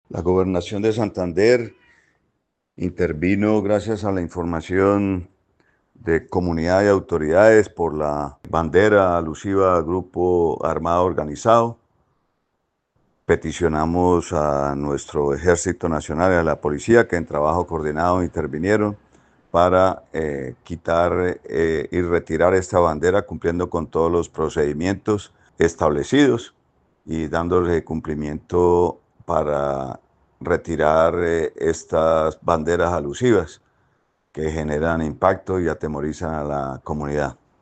Óscar Hernández, secretario del Interior de Santander